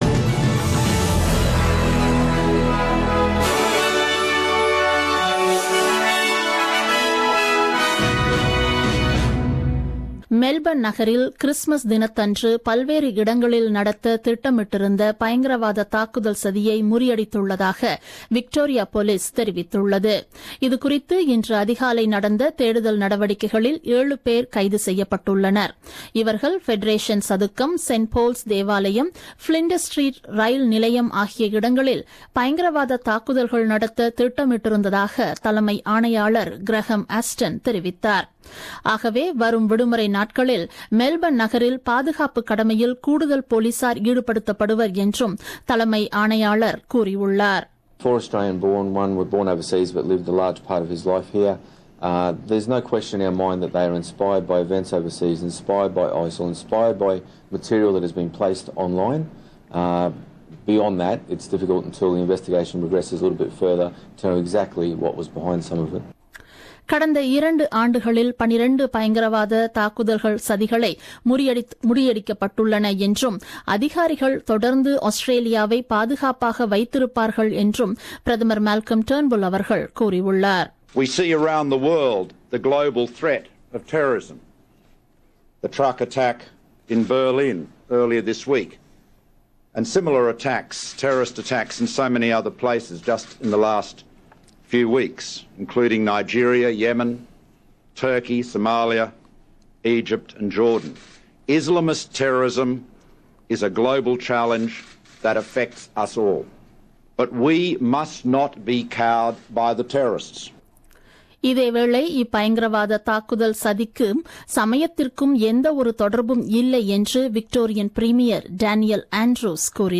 The news bulletin broadcasted on 23 Dec 2016 at 8pm.